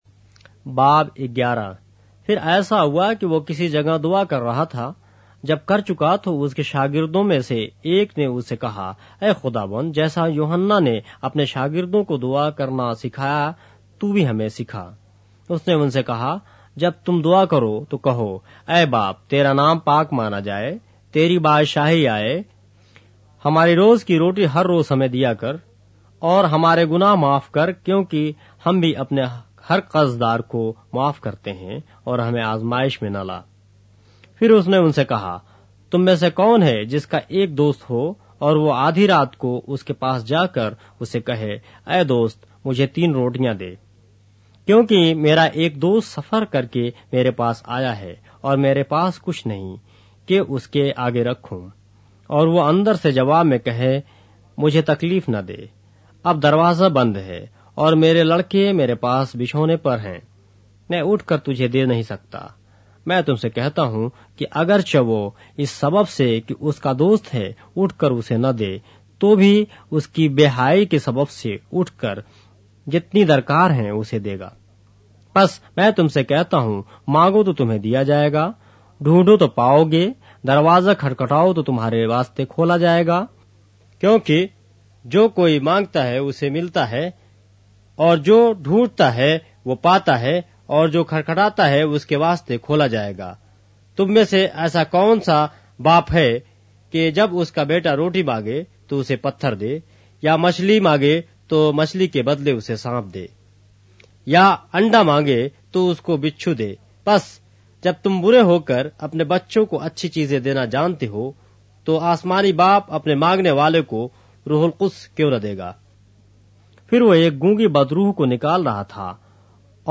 اردو بائبل کے باب - آڈیو روایت کے ساتھ - Luke, chapter 11 of the Holy Bible in Urdu